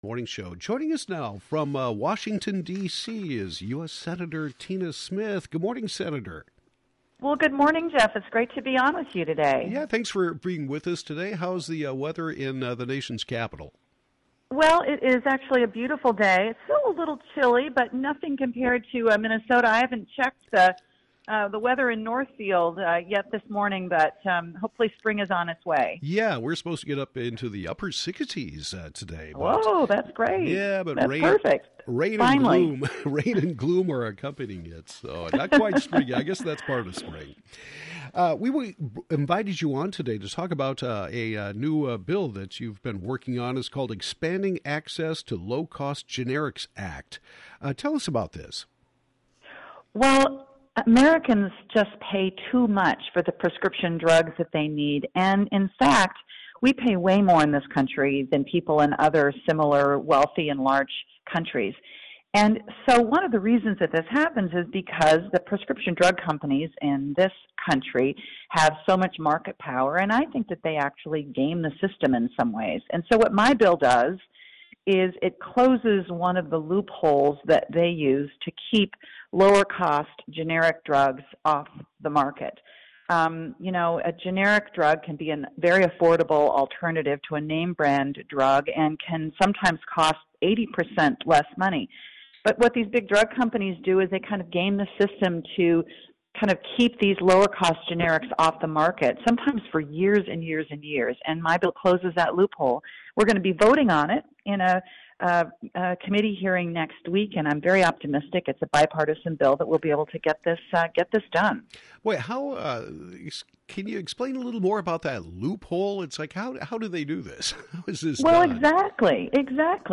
U.S. Senator Tina Smith talks about her new proposed Expanding Access to low-cost Generics Act.